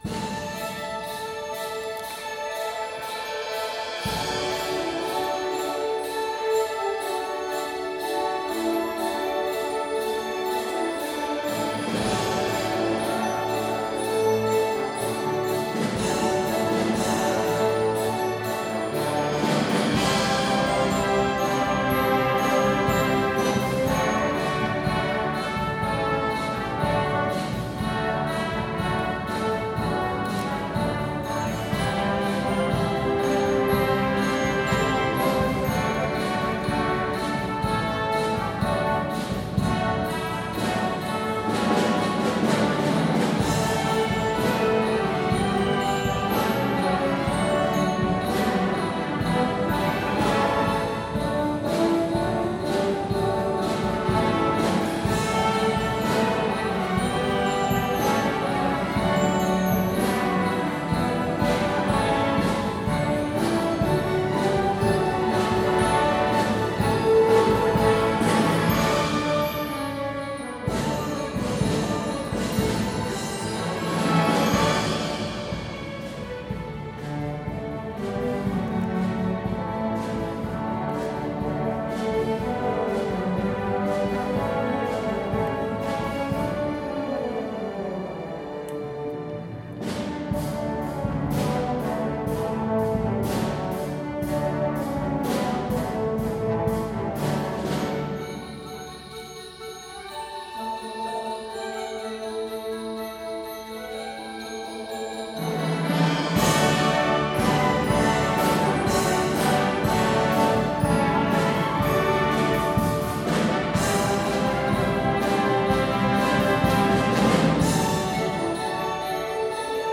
Album: Concert Noel 2017 (1er concert)